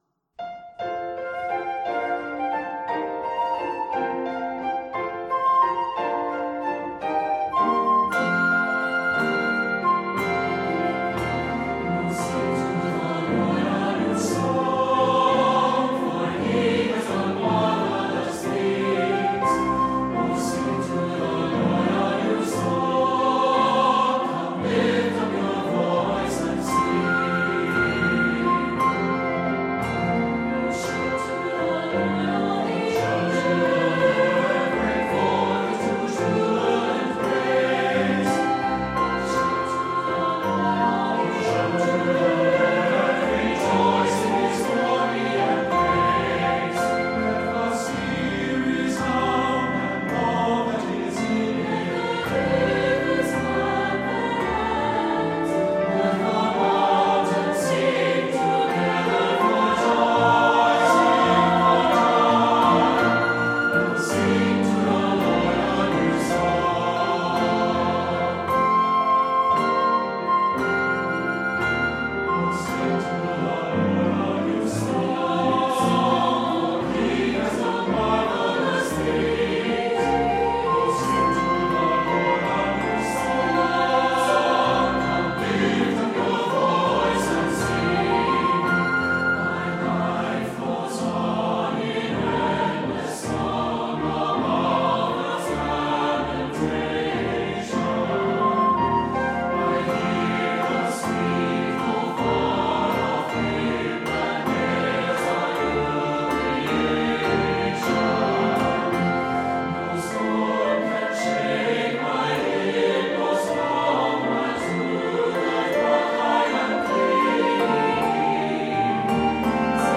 This is an exciting anthem of praise
Keys of F and G Major.